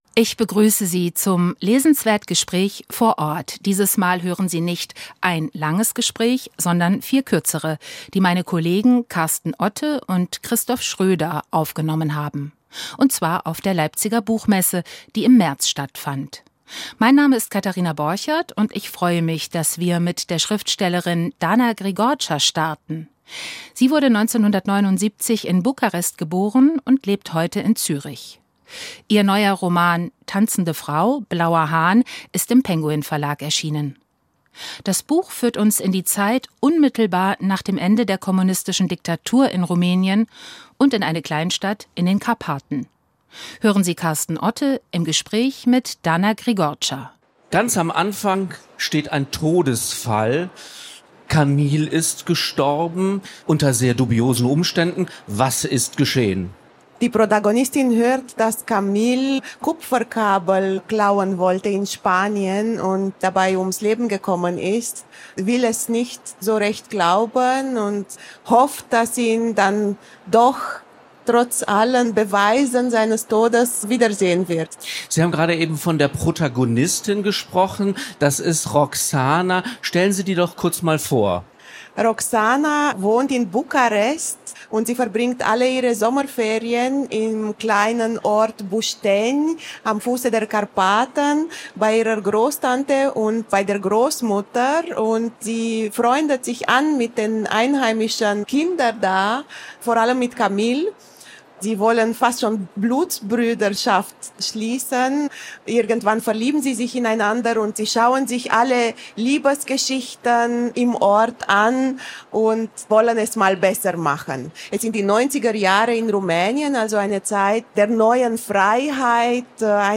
im Gespräch ~ SWR Kultur lesenswert - Literatur Podcast